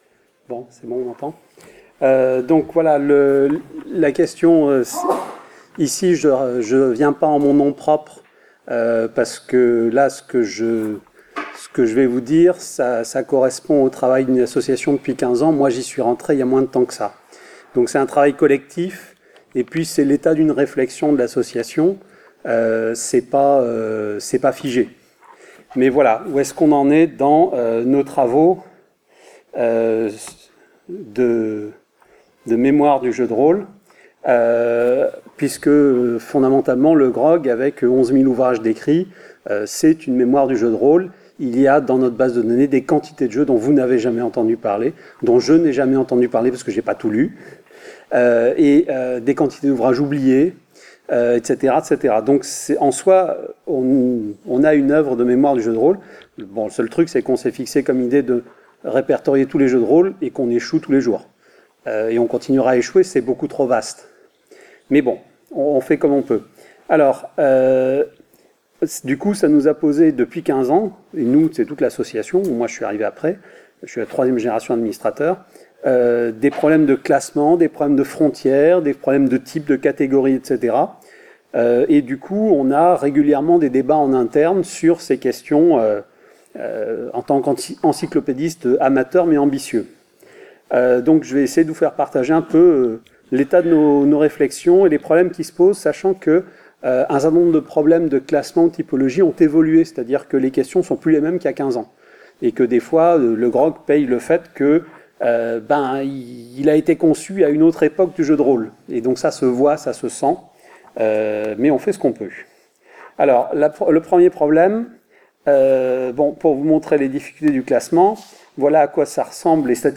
En partenariat avec les organisateurs de ces journées, vous trouverez ici les enregistrements audio des communications des différents participants à ces journées, ainsi que les supports visuels qu’ils ont utilisés.